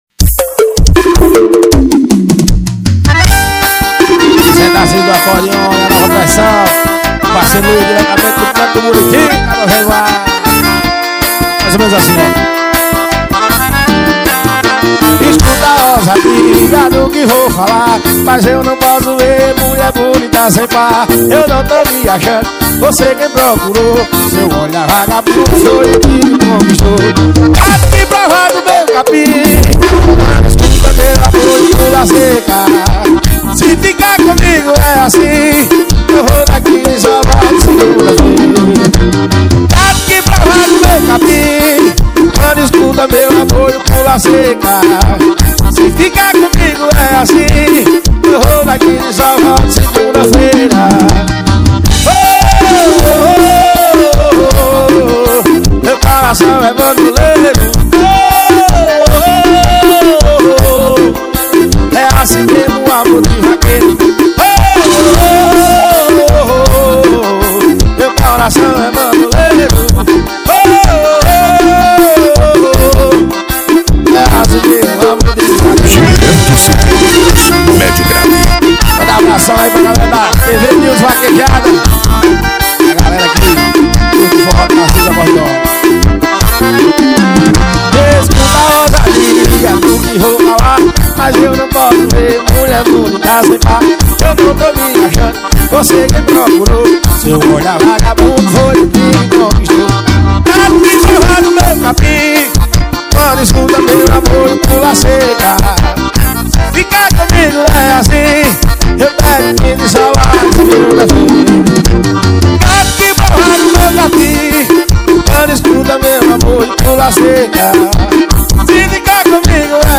2024-03-25 21:20:39 Gênero: Forró Views